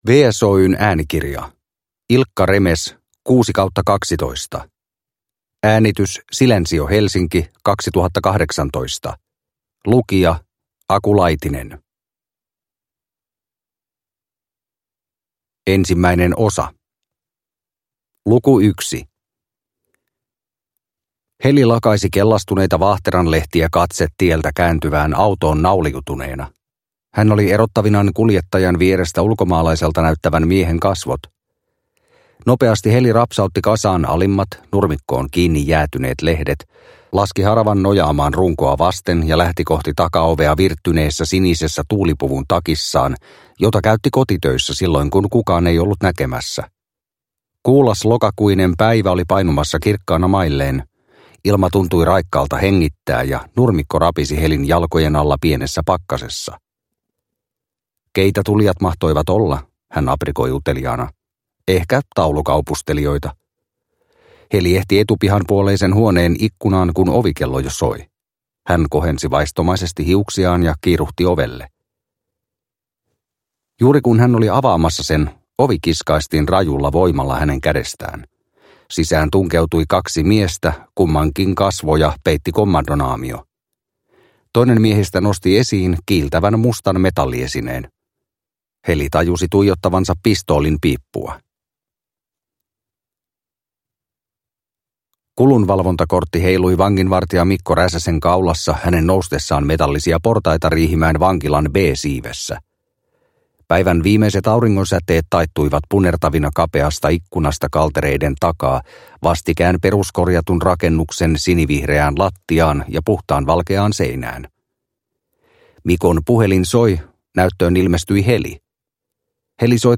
6/12 – Ljudbok – Laddas ner